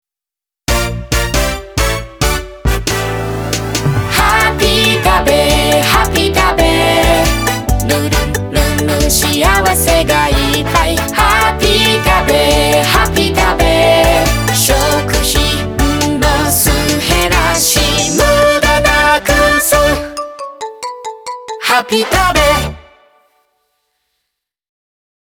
■ジングル紹介
明るく親しみやすくとても耳に残るメロディとなっています。